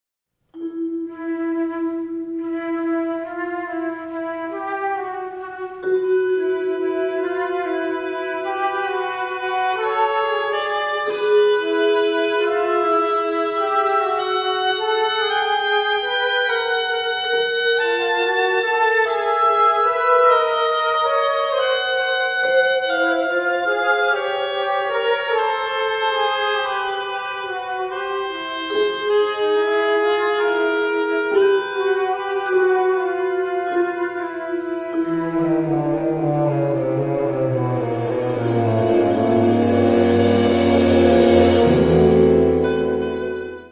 Subcategorie Hedendaagse blaasmuziek (1945-heden)
Bezetting Ha (harmonieorkest); CB (Concert Band)
Mlt: Vibraphone